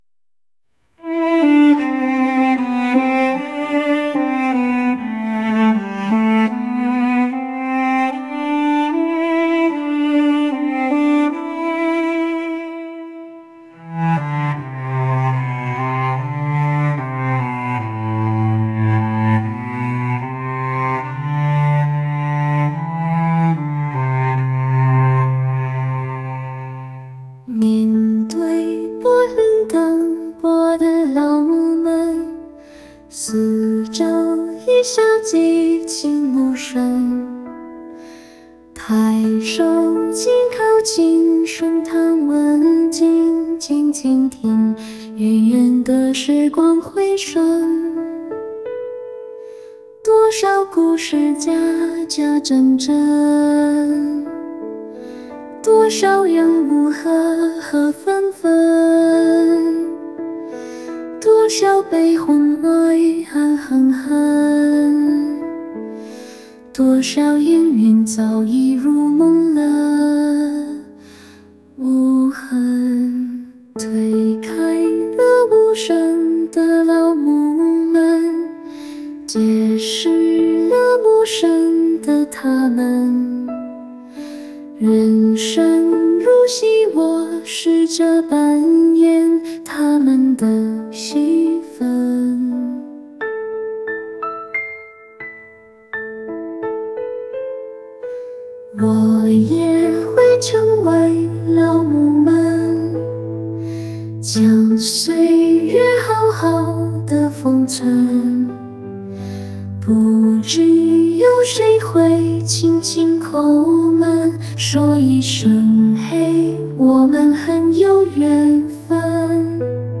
唱：小C（虚拟歌手）